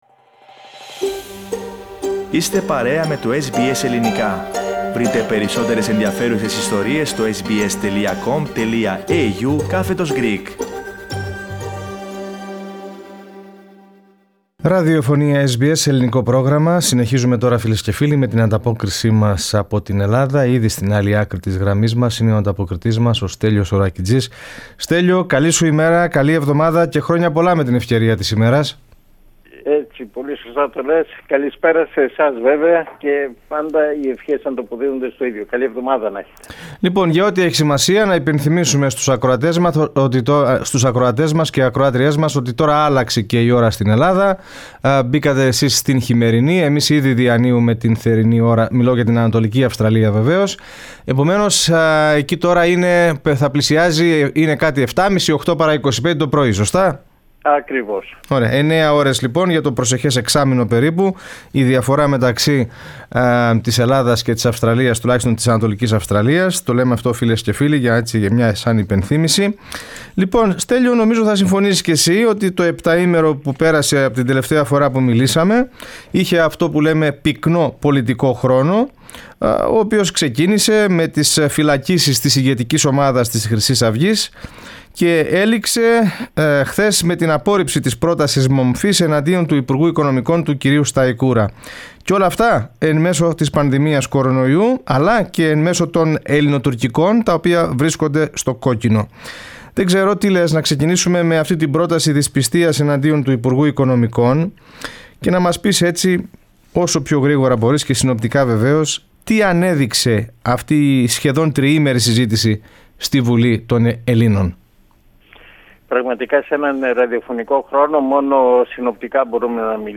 Αυτά τα θέμα της εβδομαδιαίας ανταπόκρισης από την Ελλάδα (26/10/2020) Πατήστε Play στην κεντρική φωτογραφία και ακούστε την ανταπόκριση από την Αθήνα.